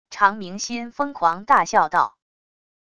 常铭心疯狂大笑道wav音频生成系统WAV Audio Player